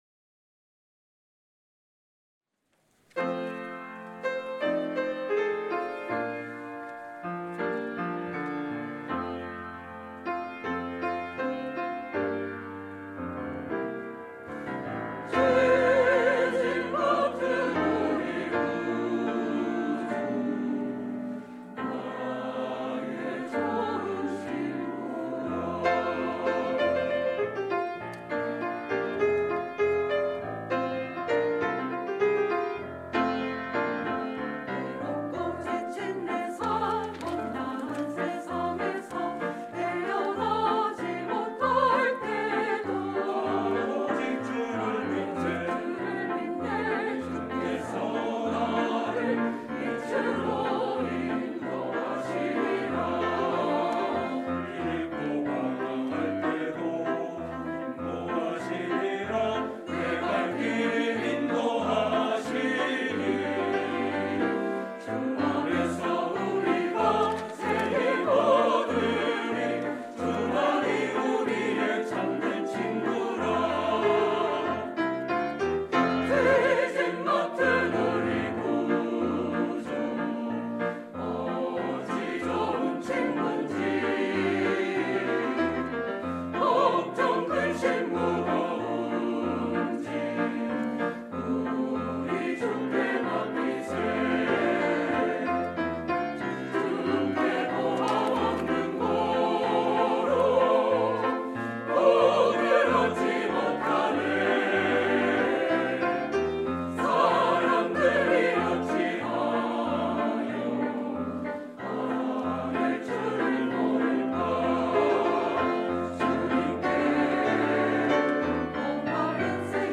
갈릴리